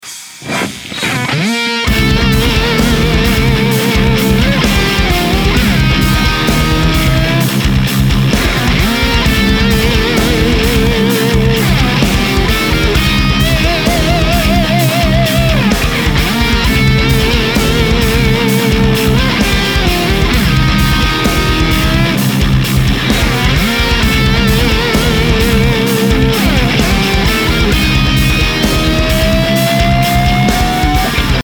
危険な雰囲気のするヘビーなイントロ完成
良い感じの緊張感が作れた気がするので
あとは全ての音源を再生させてイントロの完成です。